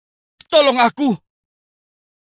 Suara Tolong Aku FF
Kategori: Suara viral